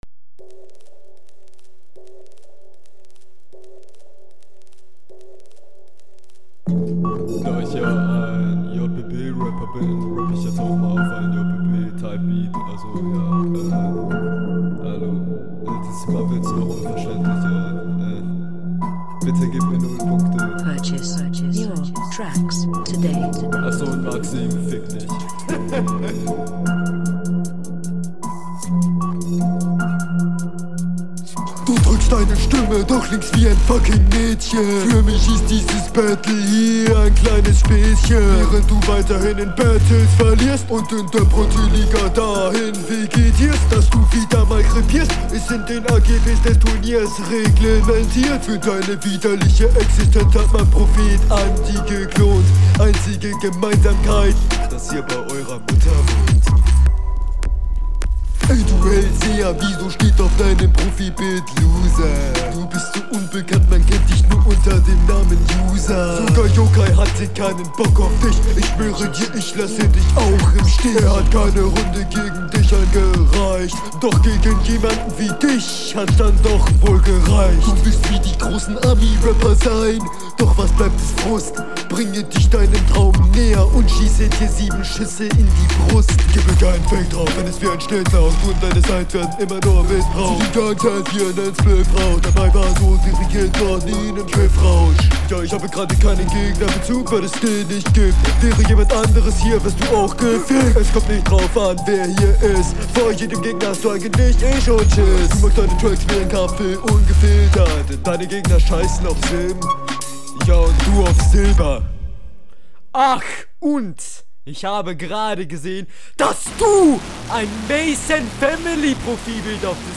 Das Intro ist sehr schwer zu verstehen.
Flow: manchmal offbeat Text:ganz ok Soundqualität: ganz gut Allgemeines: finde sein stil und seine stimme …
Flow: stimmen einsatz klingt sehr experimentel und noch nicht ganz ausgereift. du kackst auch öfters …